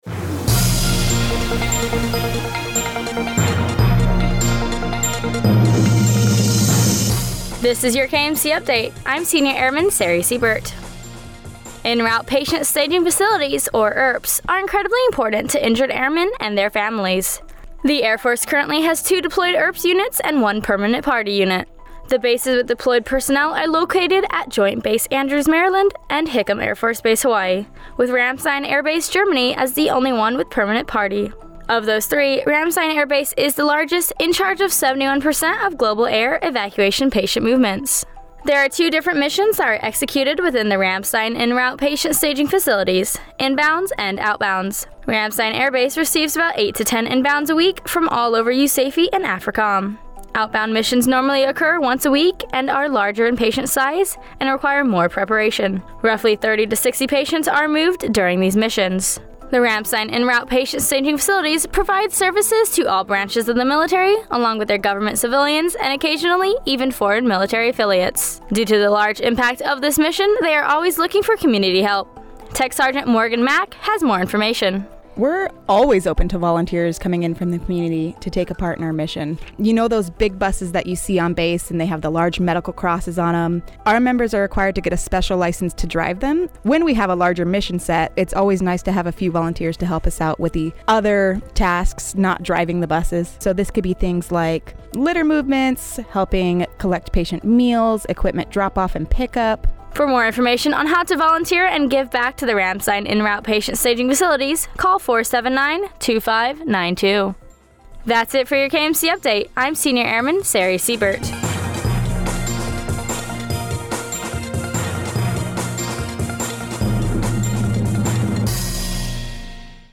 A news update highlighting Ramstein's En-route Patient Staging Facilities as the largest ERP unit in the Air Force in charge of 71% of global air evacuation patient movements.